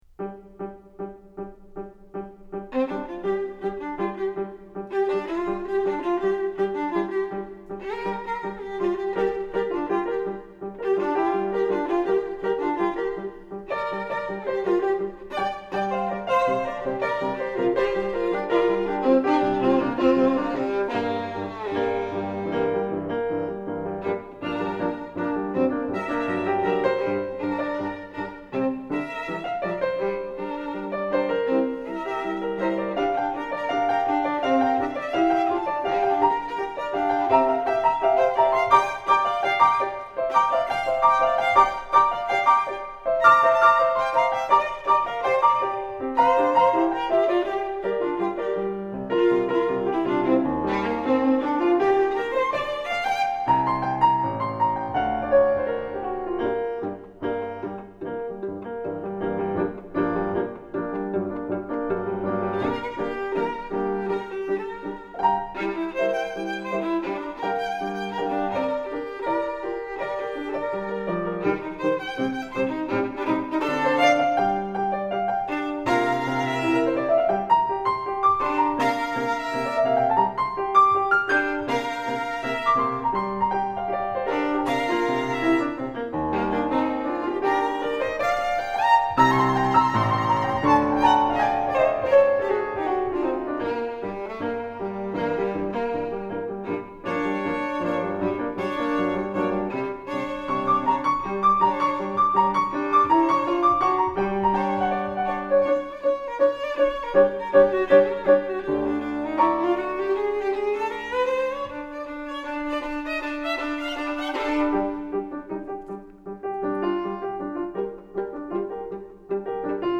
for Violin and Piano (1992)
jaunty walking-music